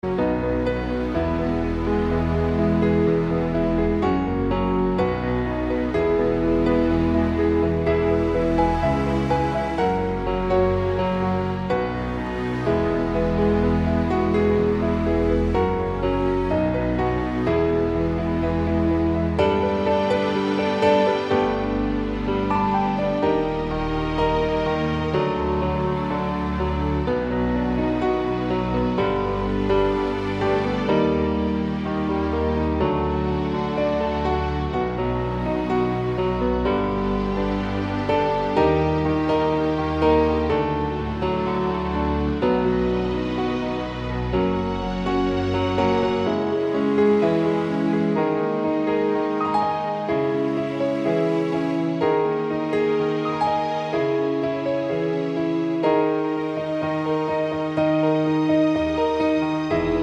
Down 2 Semitones